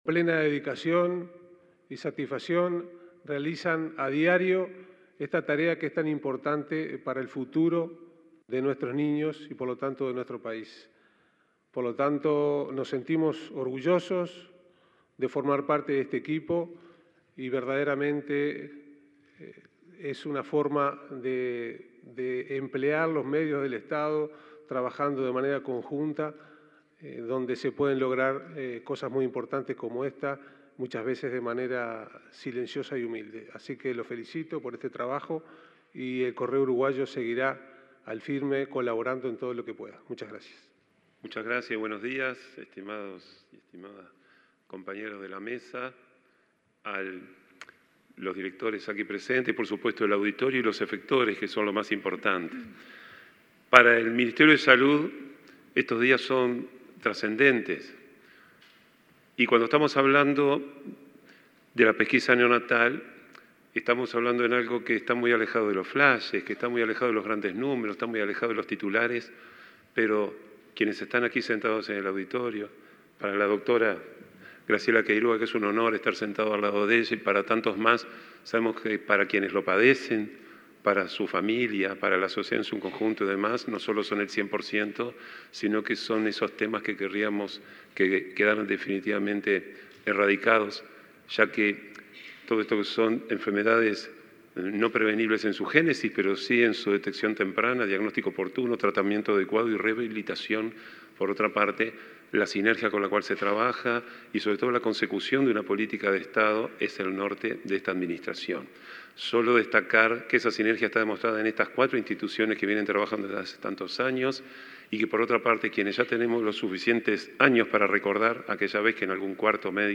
Palabras de autoridades en Jornada de Actualización de la Pesquisa Neonatal
Palabras de autoridades en Jornada de Actualización de la Pesquisa Neonatal 28/06/2022 Compartir Facebook X Copiar enlace WhatsApp LinkedIn Este martes 28, se realizó en Montevideo la Jornada de Actualización de la Pesquisa Neonatal. El presidente del Correo Uruguayo, Rafael Navarrete; el director general de Salud, Miguel Asqueta, y el presidente del Banco de Previsión Social (BPS), Alfredo Cabrera, señalaron la importancia de la temática.